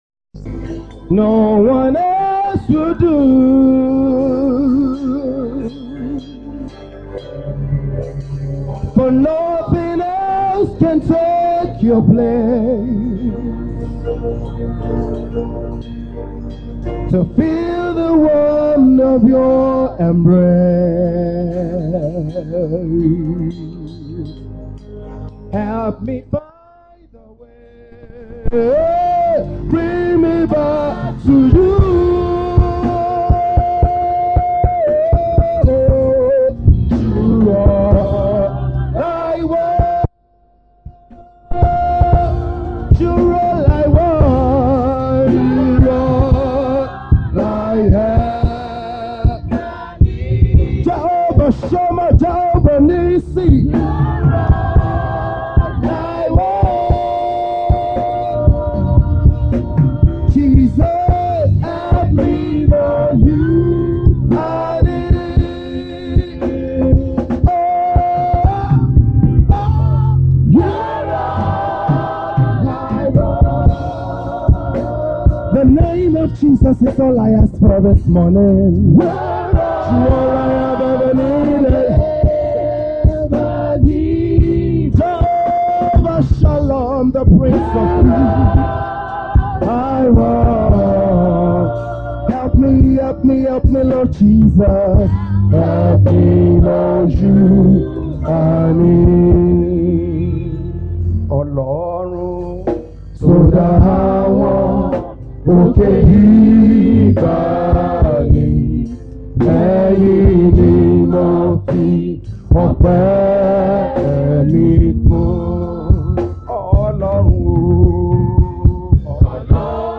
Even Jesus passed through problem Posted in Sunday Service